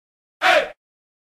Звуки операции
Звук перебираемых хирургических инструментов